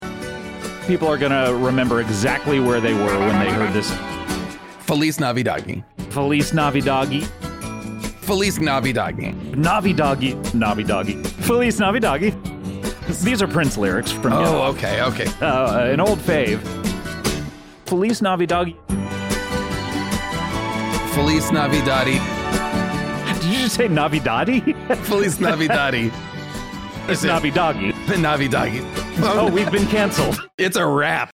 It’s sampled from episode 731, Nutshell Yourself, where host Scott and friend Jason Mantzoukas ring in the beginning of November, a.k.a. Novvy-doggy.